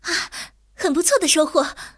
T34-85获得资源语音.OGG